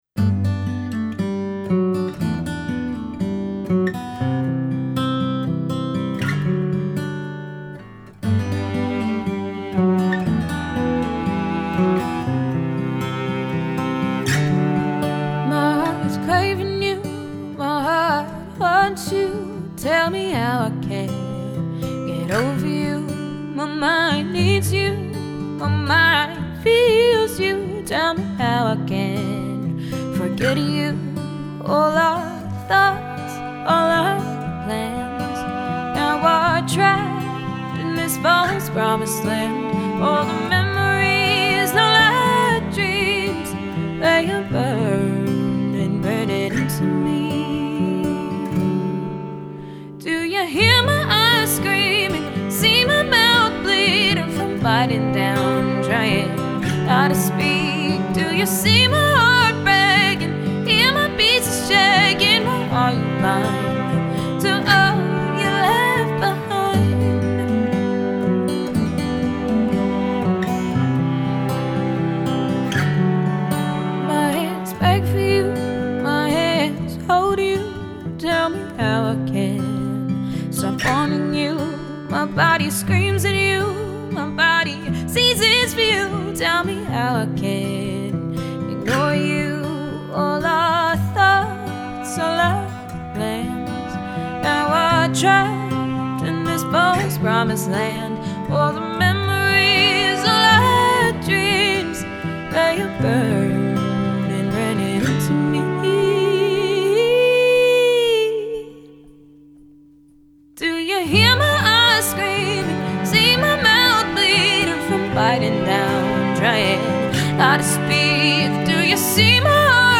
Popular